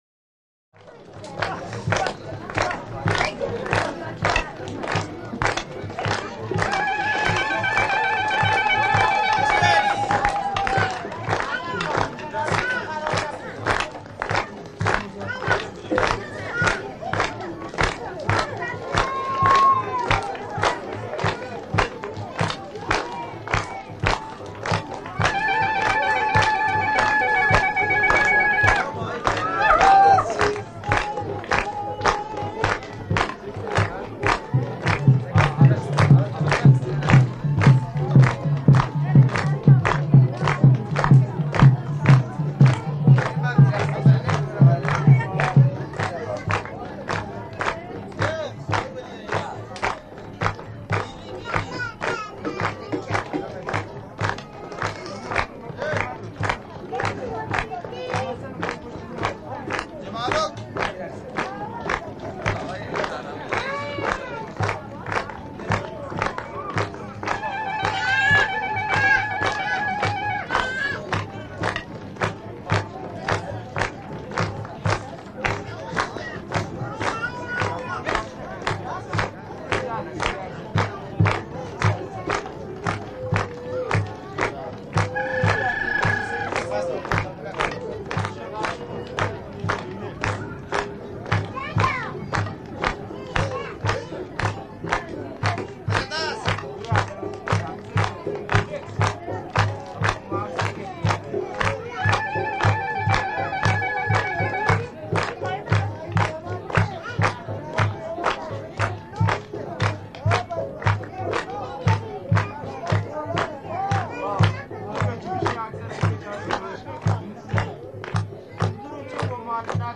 Exterior Iranian Crowd Chatters And Claps With Some Trilling And Chatter.